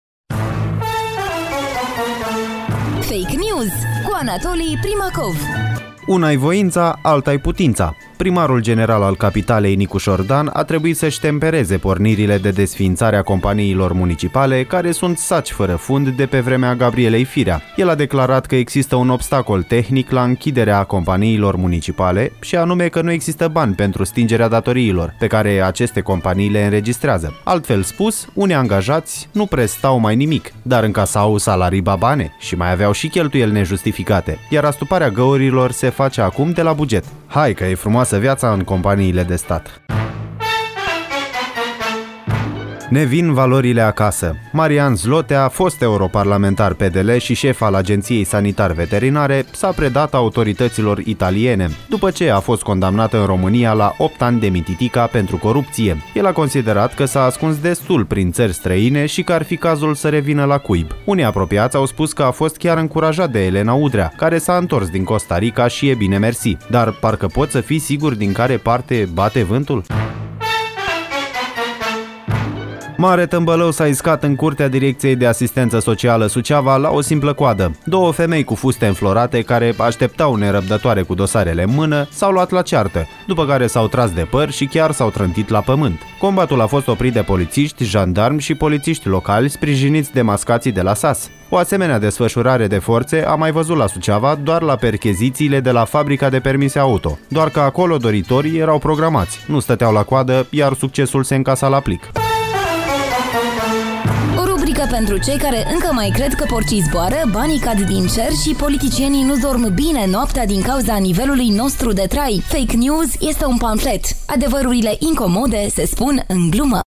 Genul programului: pamflet.